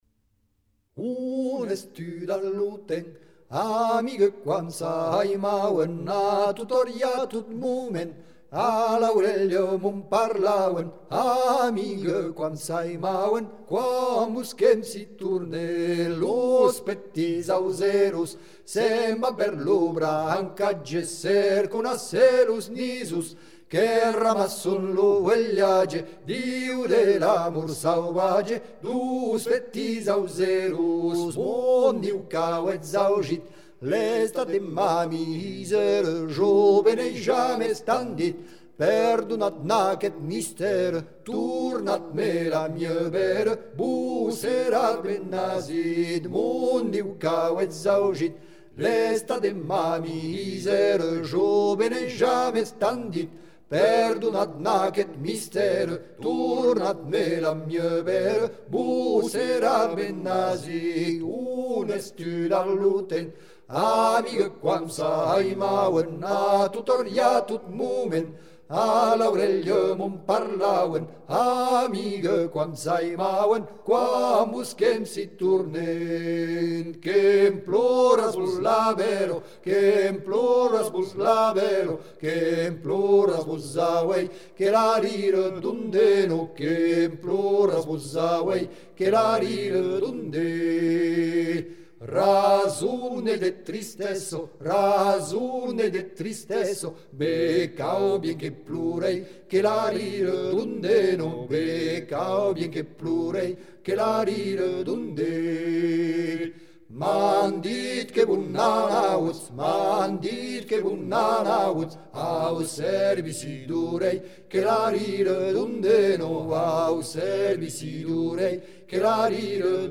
Les voix